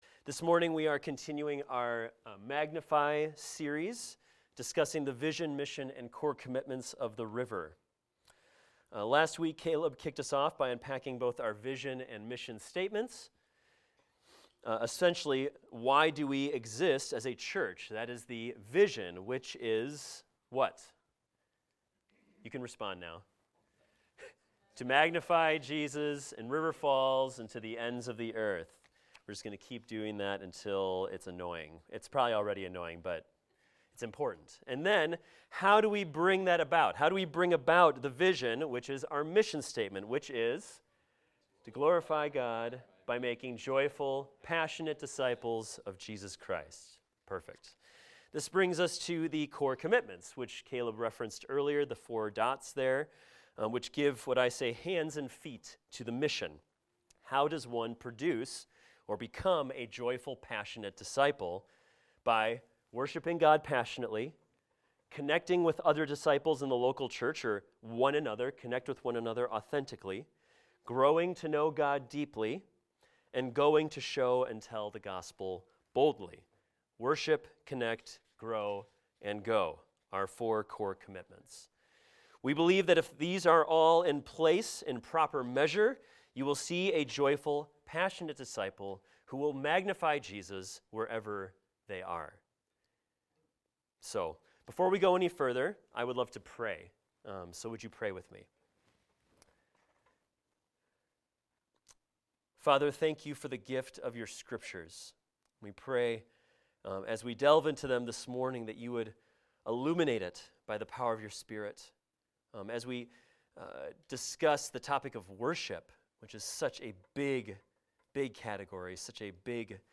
This is a recording of a sermon titled, "Worshipful Living."